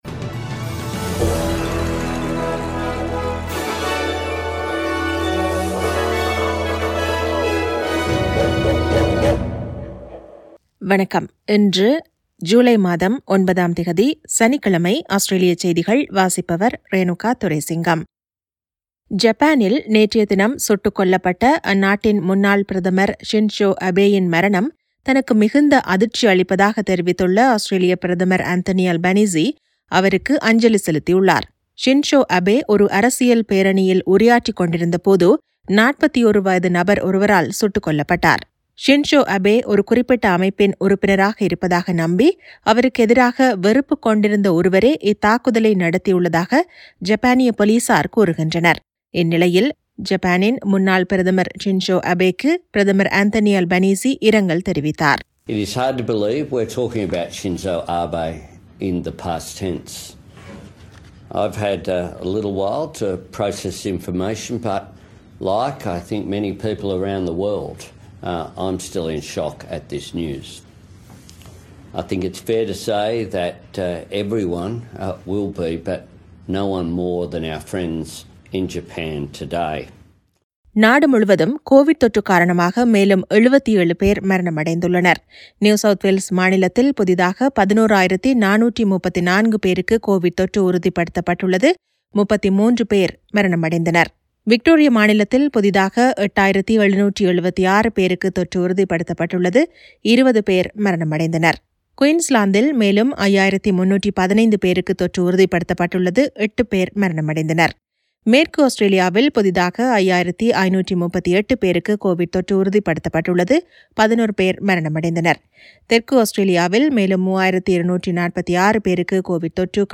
Australian news bulletin for Saturday 09 July 2022.